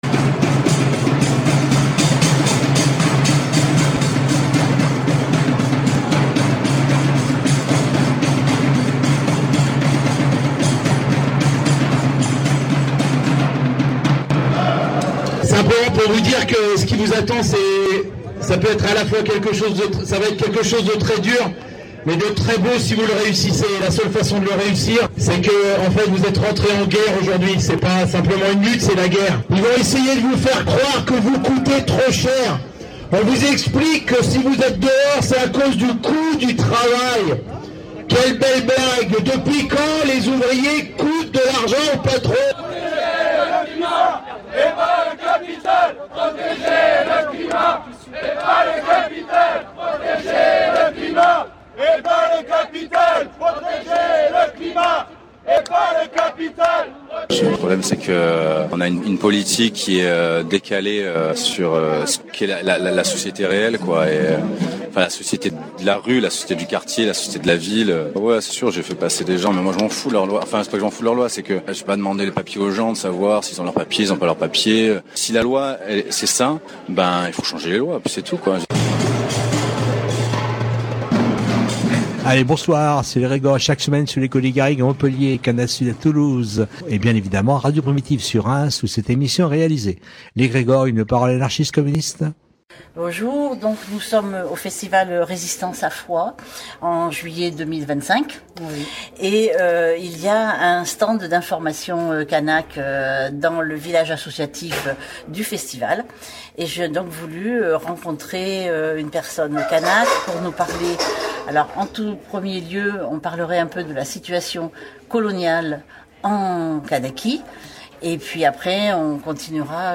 🎧 Entretien avec une militante du FLNKS - L'Égrégore
Ce soir, interview d’une militante du FLNKS réalisé début juillet à Foix, au moment où les forces politiques de Nouvelle-Calédonie étaient réunies à huis clos dans un hôtel de Bougival (Yvelines) avec des représentants de l’Etat français.